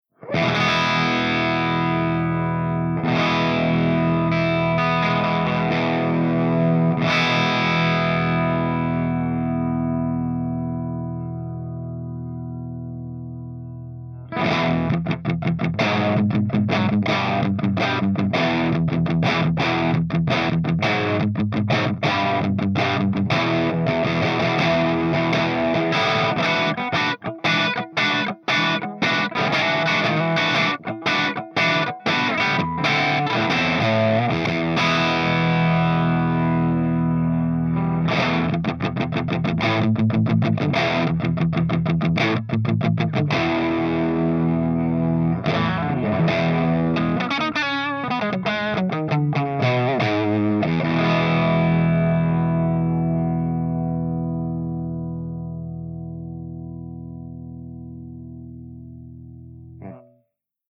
112_PLEXI_CH1+2HIGHDRIVE_GB_HB